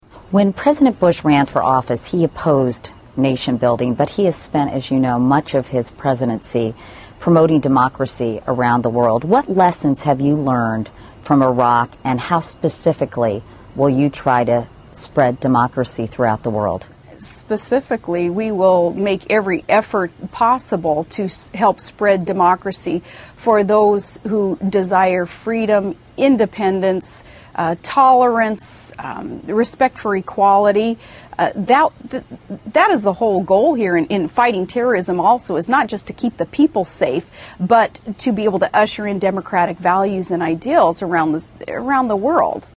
Tags: Sarah Palin Katie Couric Interview Sarah Palin Katie Couric Interview clip Katie Couric Sarah Palin Sarah Palin interview